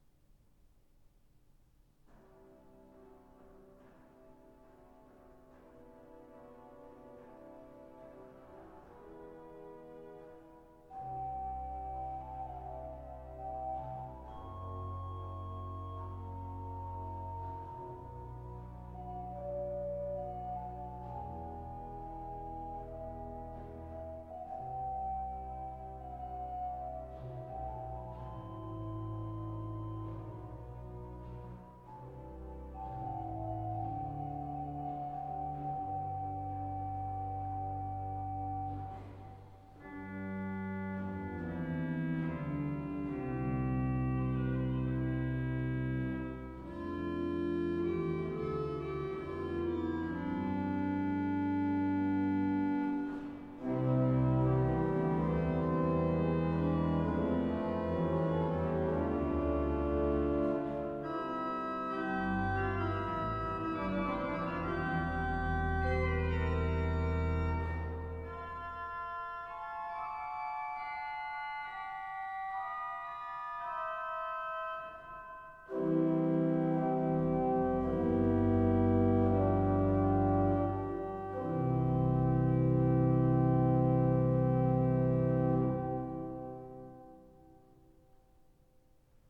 Orgel
Improvisation - Segne du Maria
Sie verfügt heute über 47 Register, 2915 Pfeifen verteilt auf vier Manuale und Pedal.
Improvisation_Segne-du-Maria.mp3